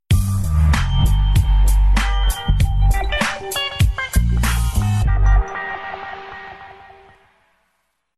Mission Complete Sound Effect Free Download